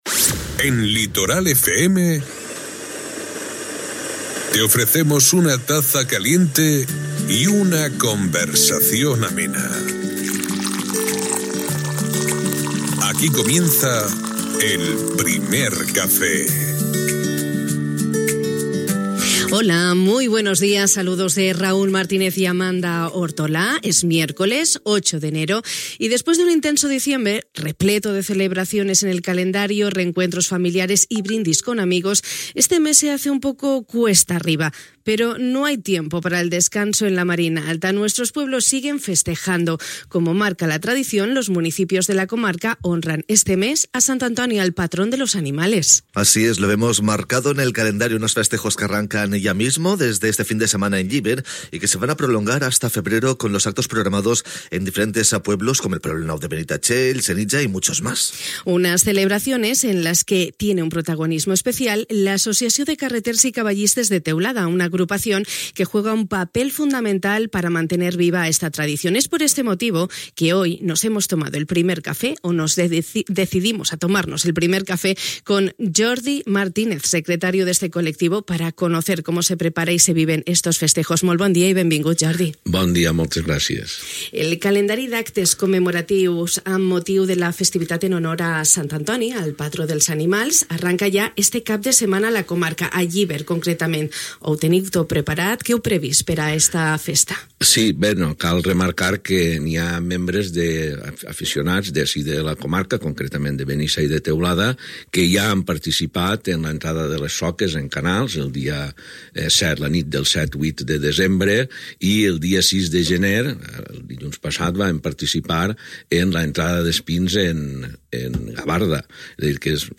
Amb el nostre convidat hem pogut conéixer com es preparen i s’esperen viure aquestes festes que formen part de les nostres tradicions més arrelades i que són una manera de celebrar l’amor pels animals.